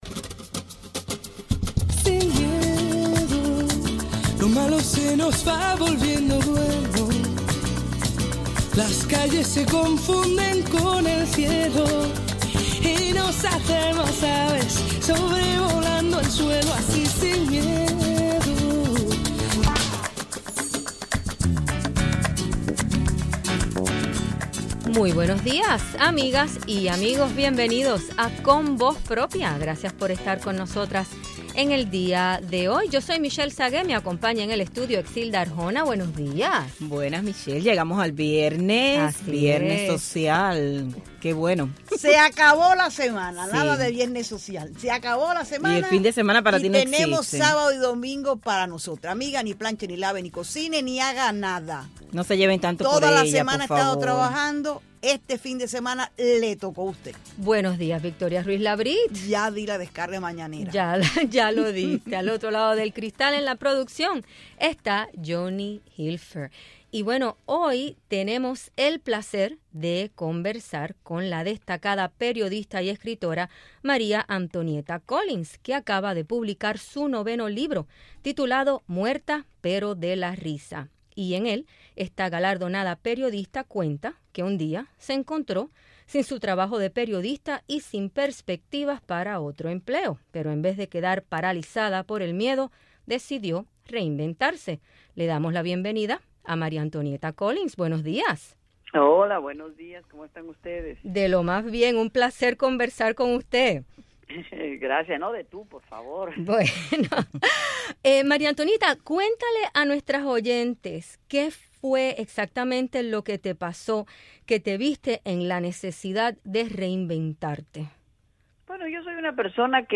Entrevistamos a la destacada periodista y escritora Maria Antonieta Collins sobre su mas reciente libro "Muerta, pero de risa"